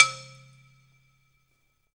Index of /90_sSampleCDs/USB Soundscan vol.02 - Underground Hip Hop [AKAI] 1CD/Partition D/06-MISC
BALAFON 1 -L.wav